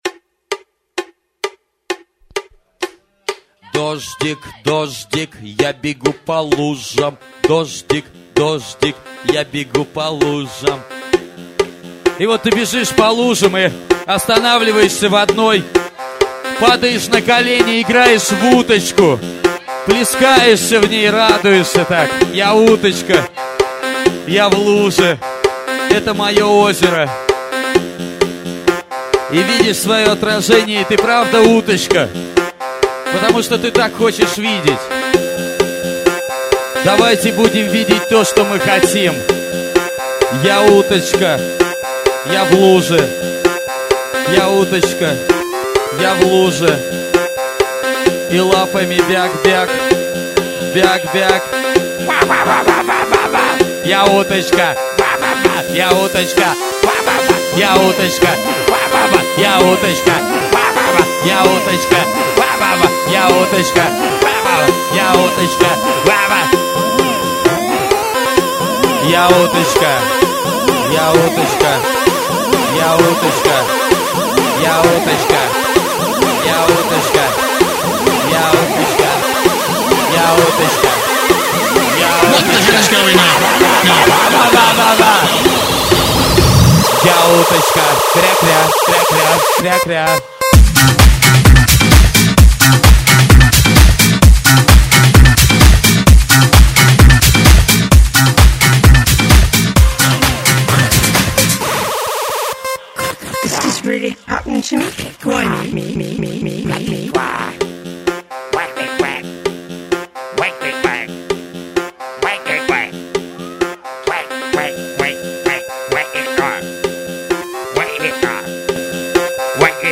музыкальную композицию.